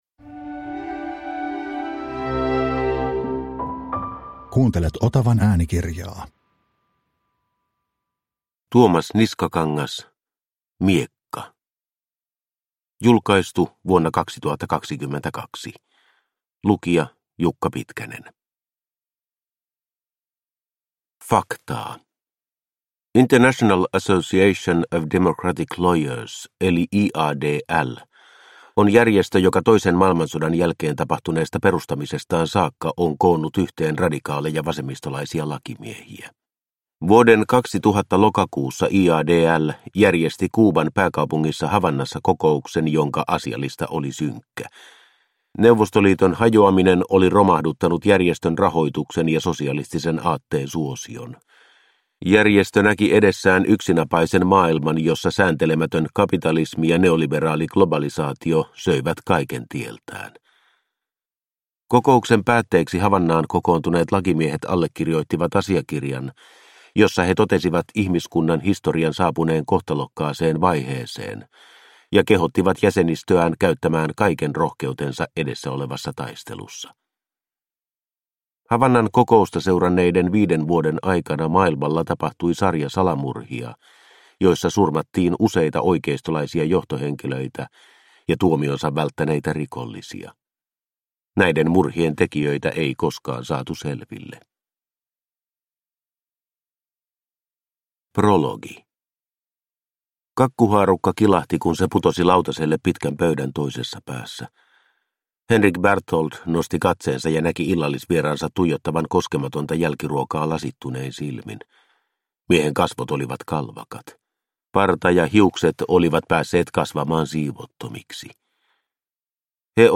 Miekka – Ljudbok – Laddas ner